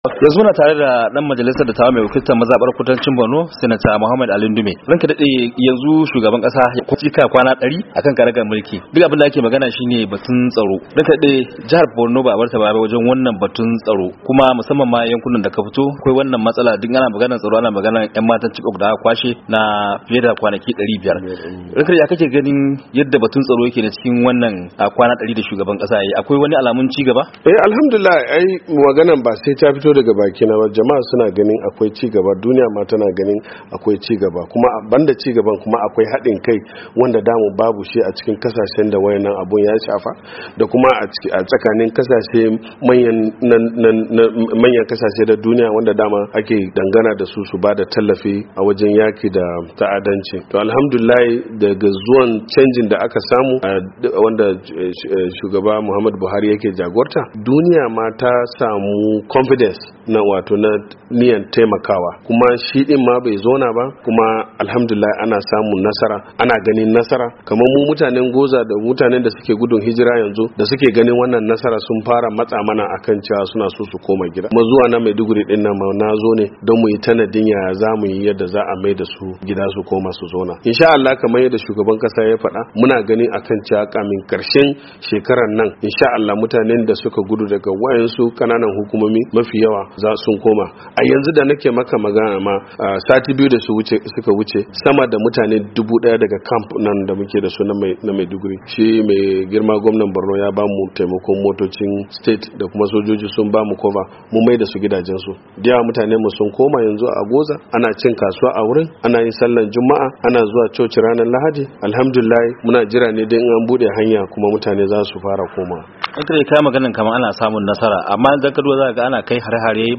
A firar da yayi da Muryar Amurka Sanata Ali Ndime mai wakiltar kudancin Borno yankin da kungiyar Boko Haram ta fi daidaitawa ya bayyana irin nasarorin da shugaba Buhari ya samu cikin kwanaki dari akan mulki.